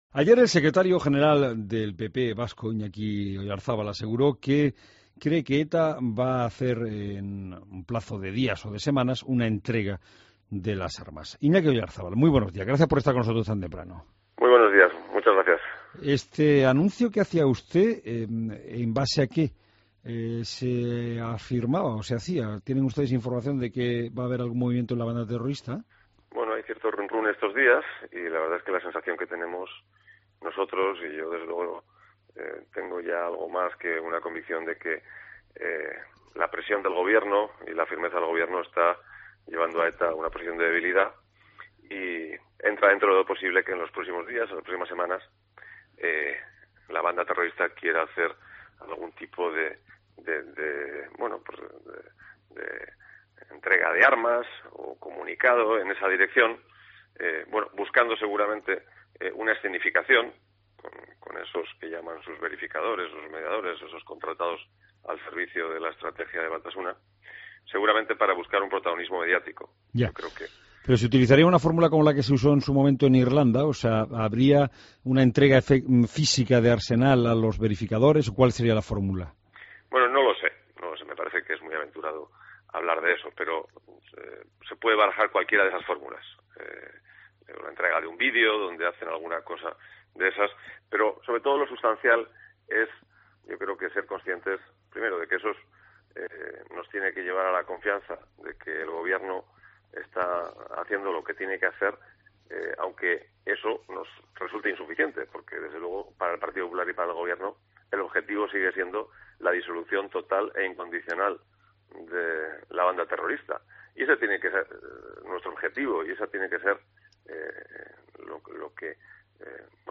Escucha la entrevista a Iñaki Oyarzabal en COPE